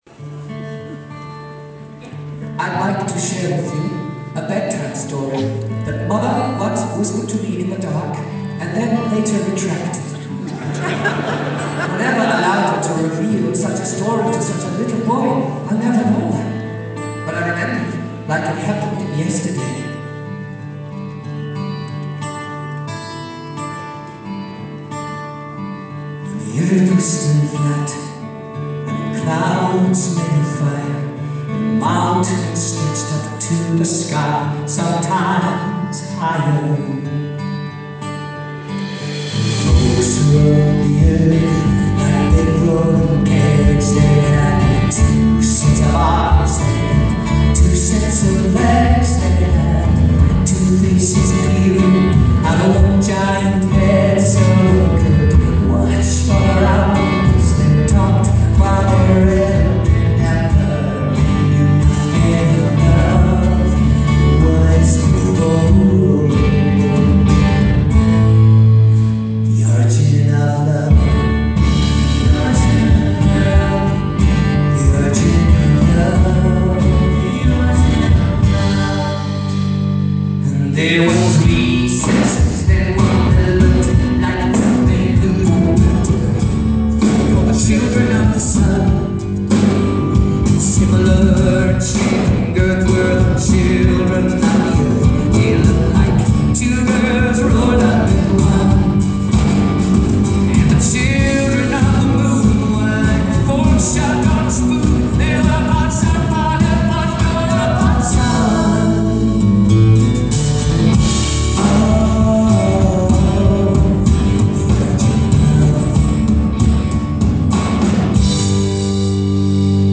I love the tone of his voice on the slower songs.